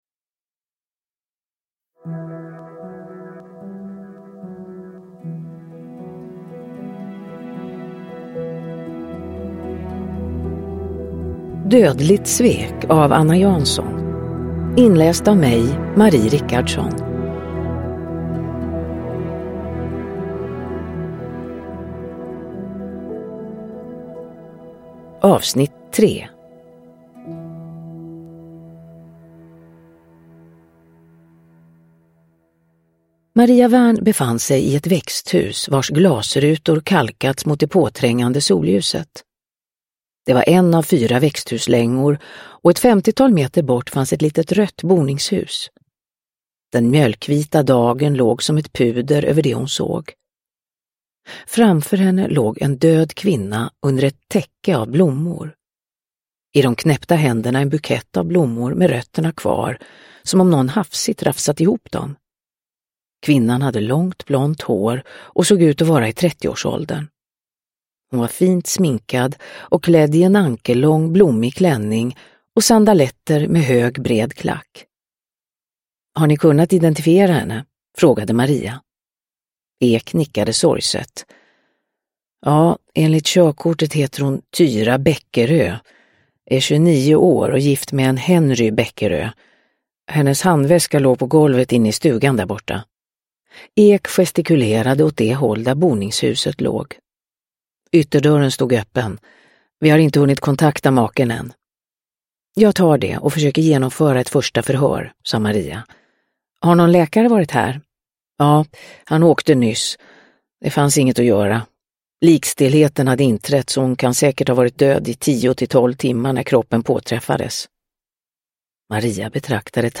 Dödligt svek - 3 – Ljudbok – Laddas ner
Uppläsare: Marie Richardson